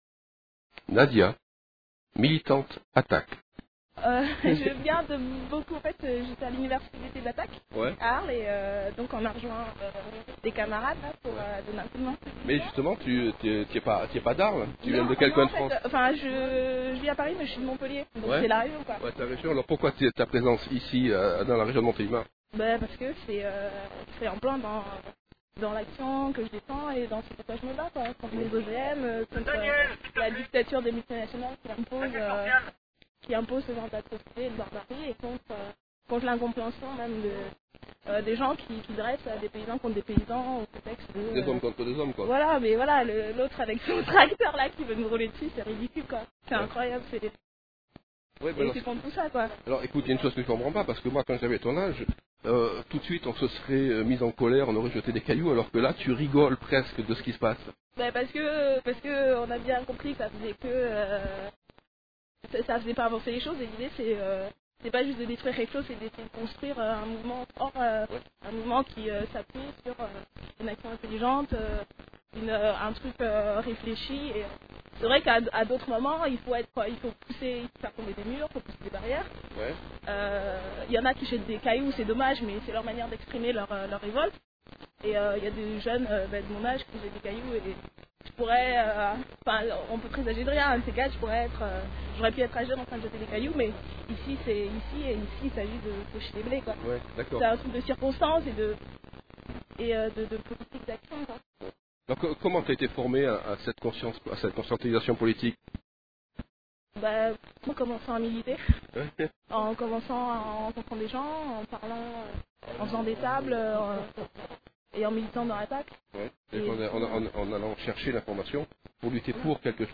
Les Interviews de Radio-Méga
le 26 Août 2001 à Salette